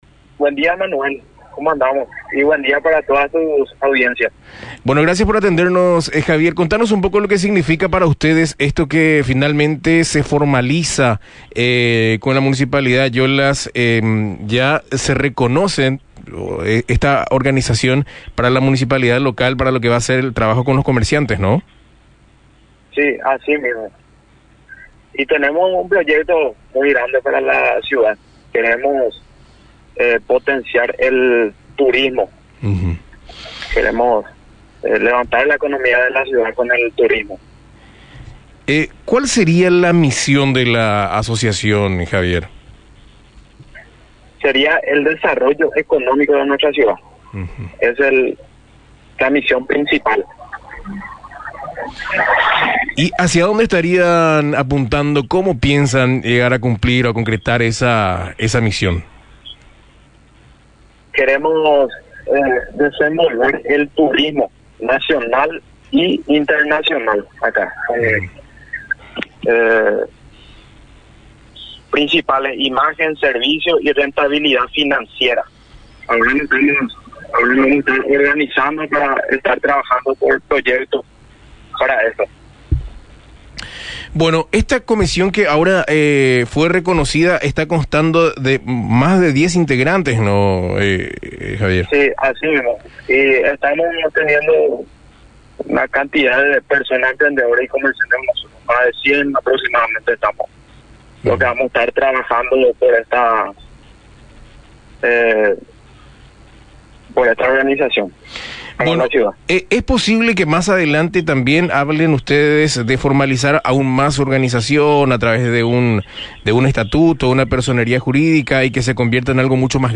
habló en Misión FM sobre el objetivo de la organización.